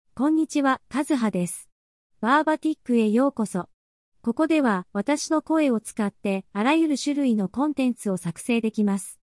Kazuha — Female Japanese AI voice
Kazuha is a female AI voice for Japanese.
Voice sample
Listen to Kazuha's female Japanese voice.
Kazuha delivers clear pronunciation with authentic Japanese intonation, making your content sound professionally produced.